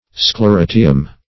Sclerotium \Scle*ro"ti*um\, n.; pl. Sclerotia. [NL., fr. Gr.